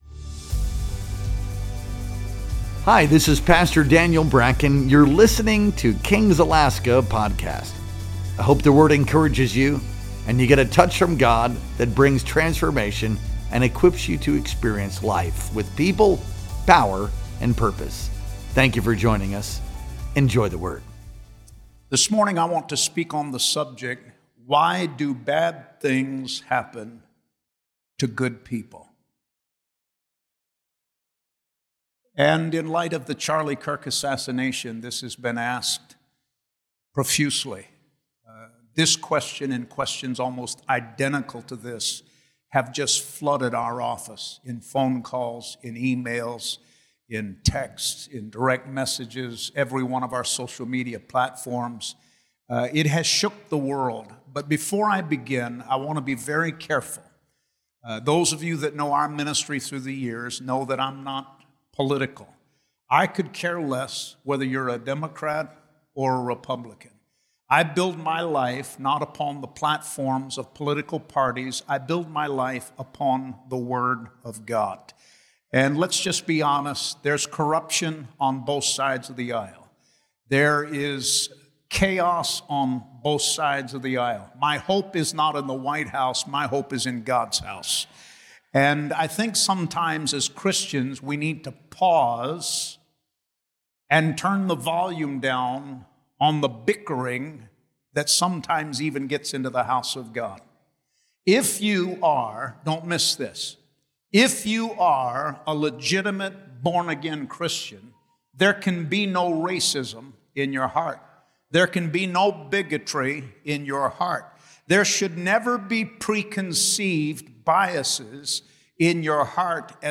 Our Sunday Morning Worship Experience streamed live on October 5th, 2025.